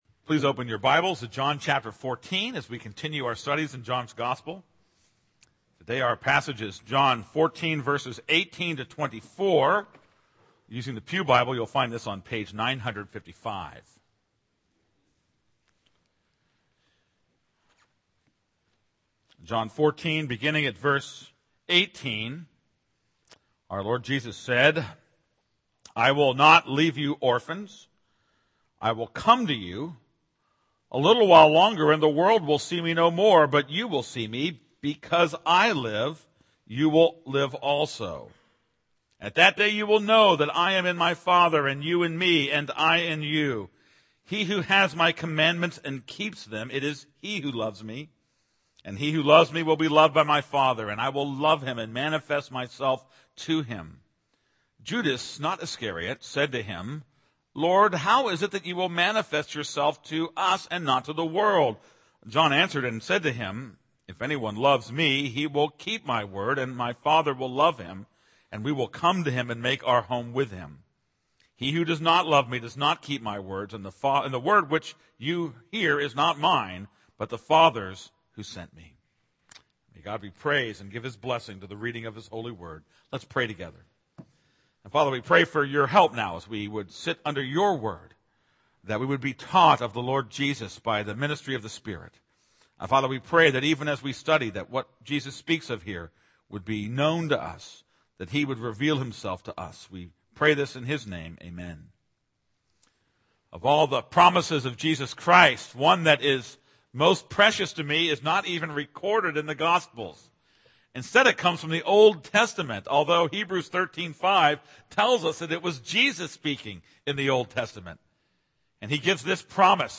This is a sermon on John 14:18-24.